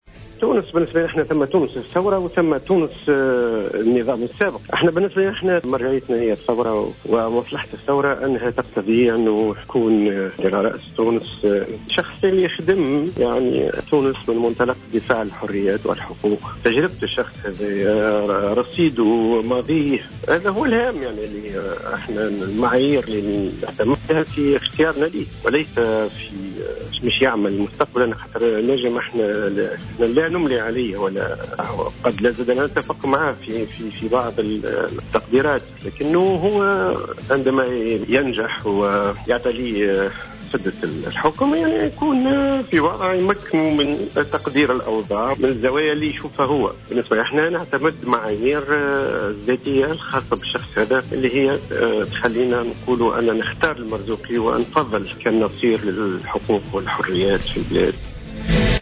أكد عبد الرؤوف العيّادي رئيس حركة وفاء في تصريح للجوهرة أف أم اليوم الثلاثاء دعم الحركة للمترشح المستقل للدور الثاني للانتخابات الرئاسية محمد المنصف المرزوقي.